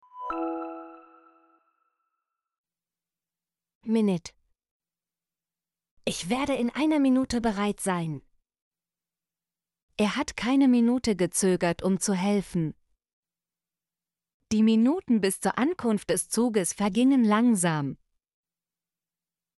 minute - Example Sentences & Pronunciation, German Frequency List